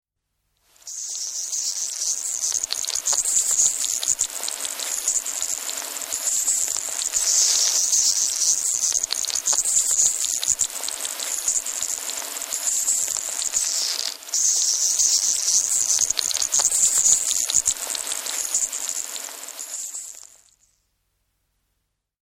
Звуки рака, краба
Шипение краба издающего звук ртом